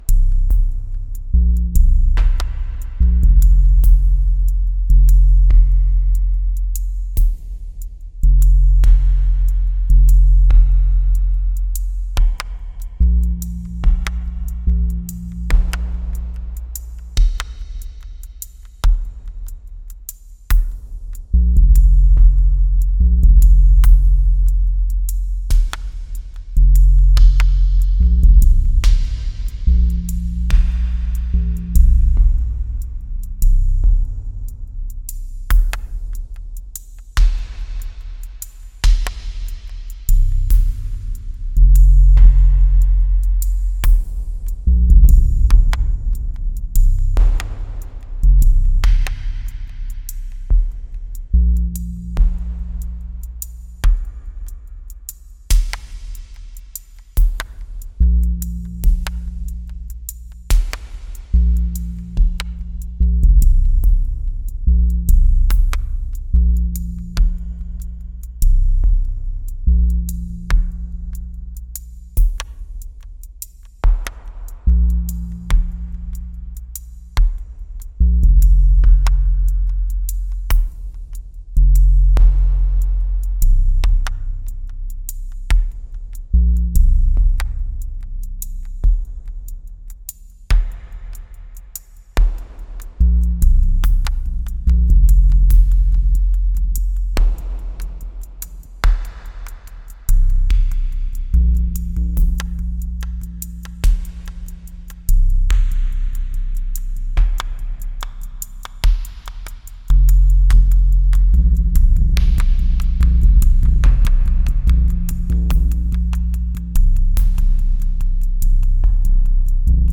morning empty jam for jam 21